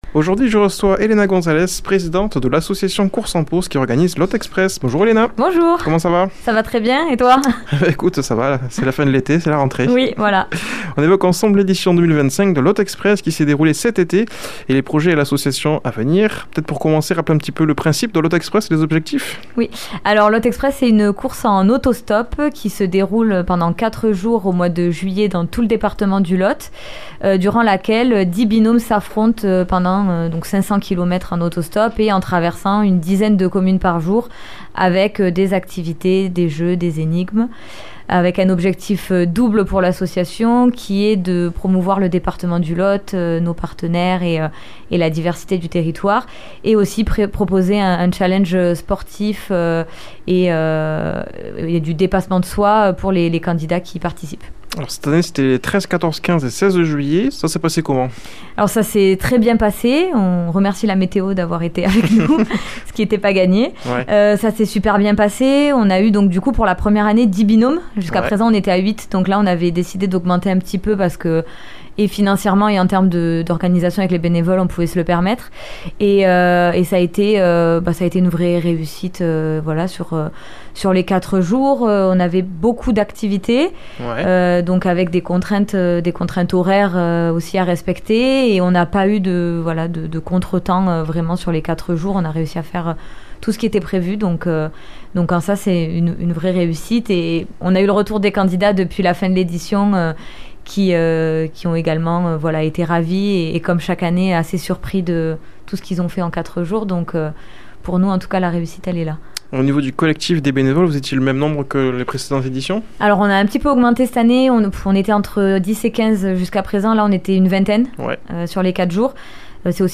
invitée au studio